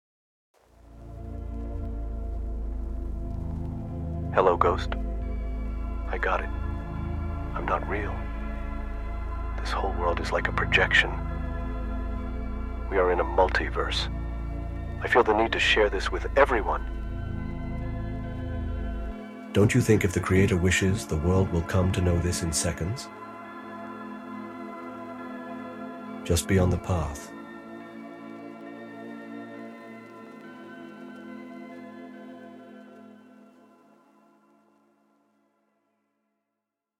Punjabi Music Album